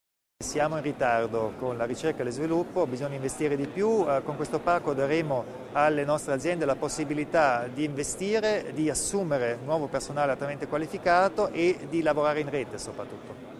Il Presidente Kompatscher spiega l'importanza del Parco Tecnologico